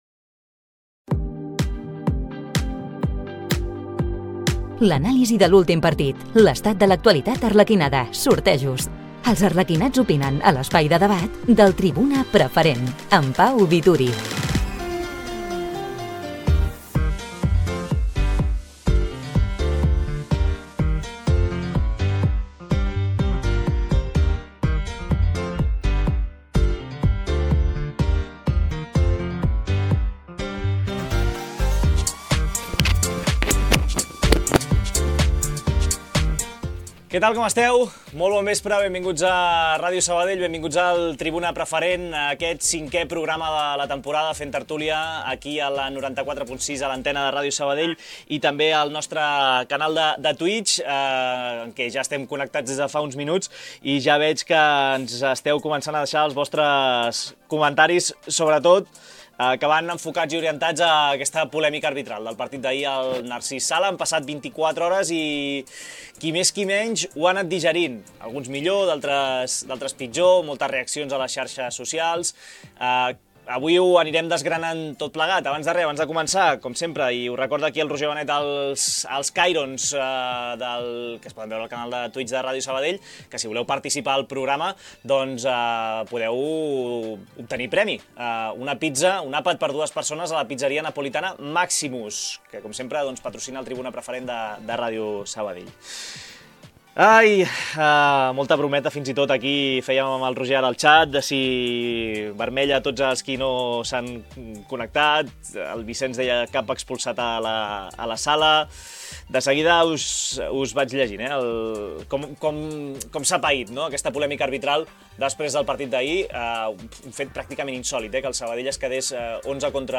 La tertúlia del Centre d’Esports Sabadell. Un espai d’opinió i debat al voltant de l’actualitat i el futur del club arlequinat.